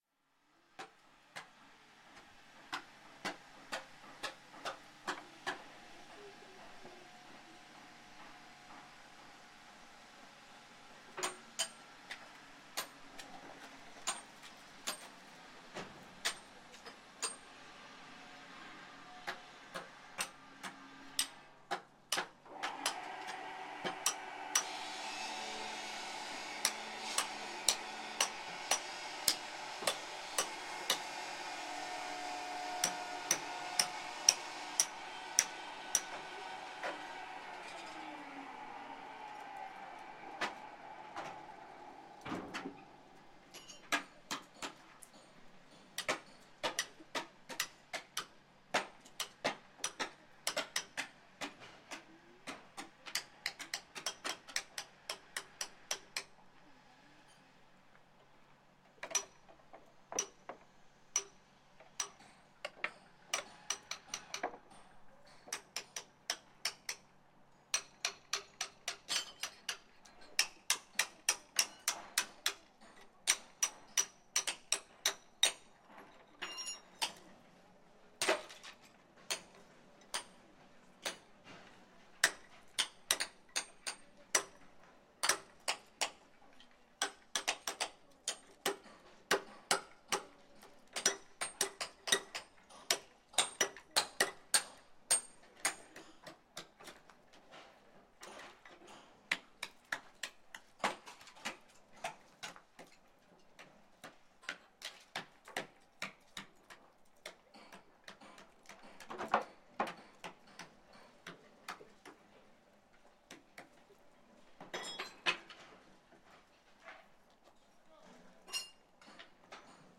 Gravação do som numa pedreira de Ardósia. Gravado com Fostex FR-2LE e um par de microfones shotgun Rode NTG-2
Tipo de Prática: Paisagem Sonora Rural
Canelas-Pedreira-de-Xisto.mp3